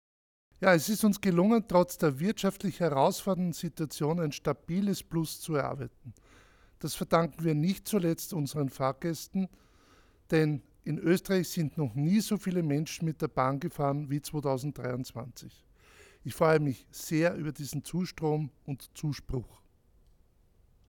ÖBB Bilanz 2023 Statement